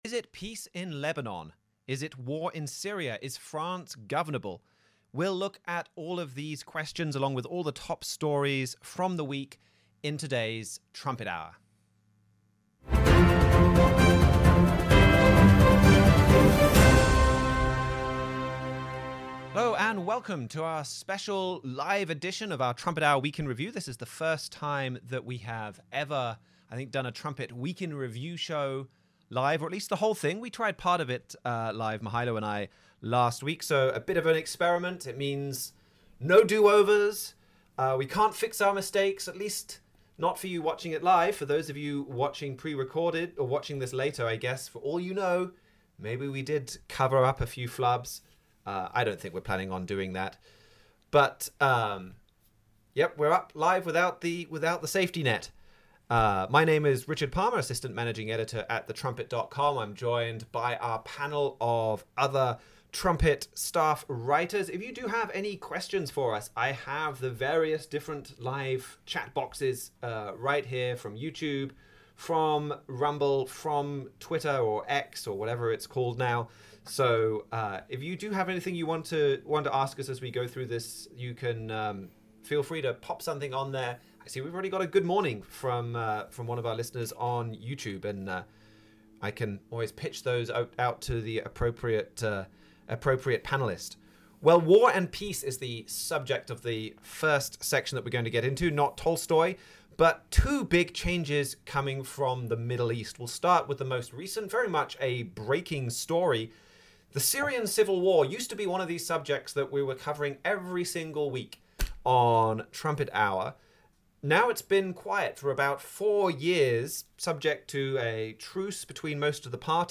We went live for our week in review today, allowing us to give you up-to-the-minute news as Syria plunges back into chaos.